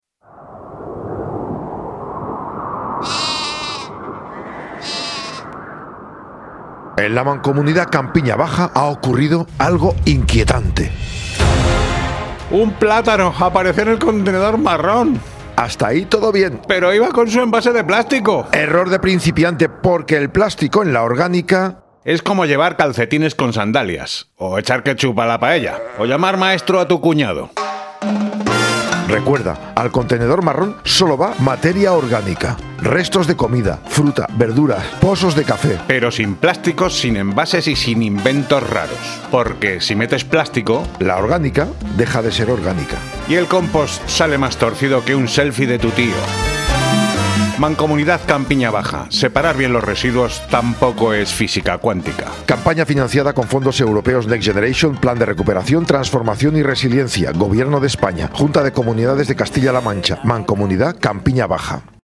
La cuña que se te mete en la cabeza
Sí, también lo hemos contado por la radio.
Cuna-de-Radio.mp3